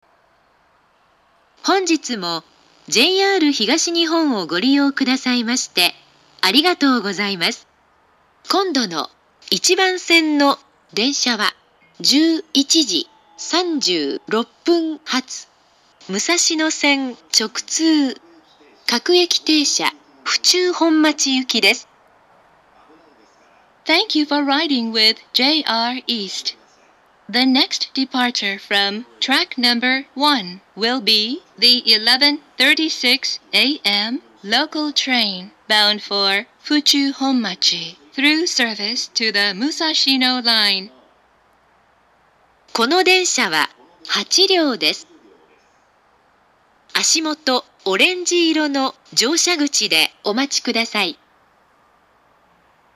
２０１６年７月上旬頃には放送装置が更新され、発車メロディーの音質が向上しました。
１番線到着予告放送
shinkiba1bansen-jihatu2.mp3